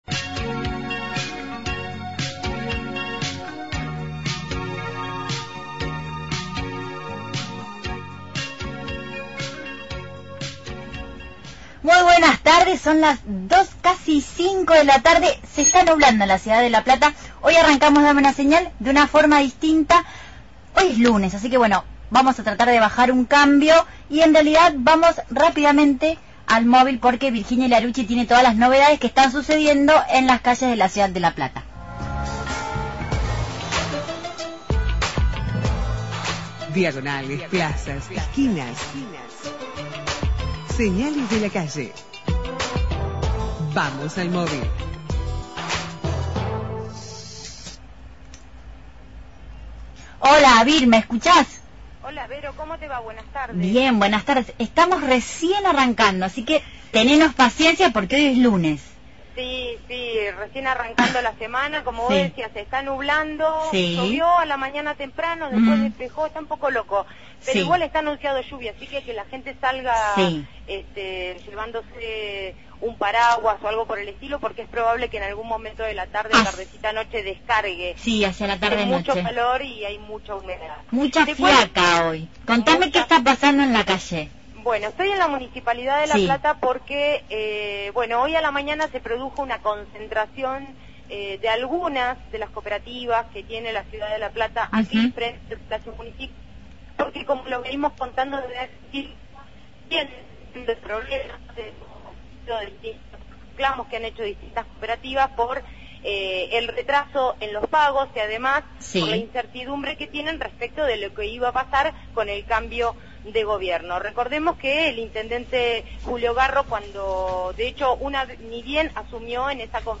MÓVIL/ Falta de pago a cooperativistas municipales – Radio Universidad